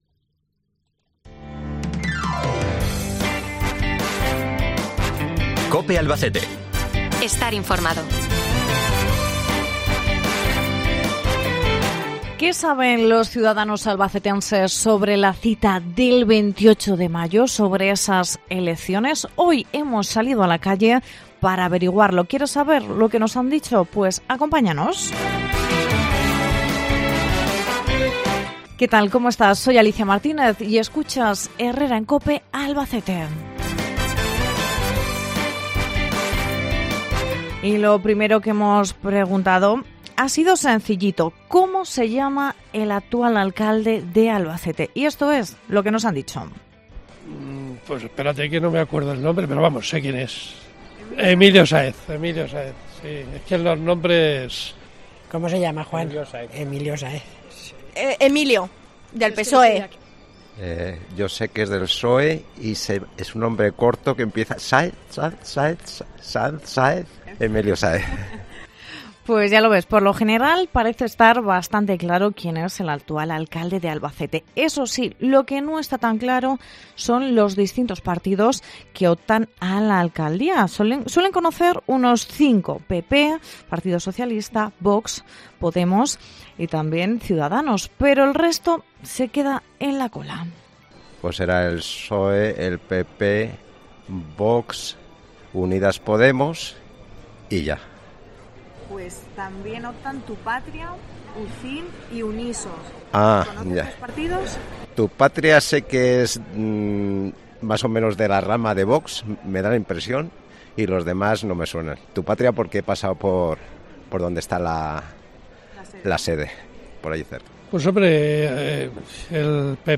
¿Qué saben los albacetenses de la cita del 28M? Hoy salimos a la calle...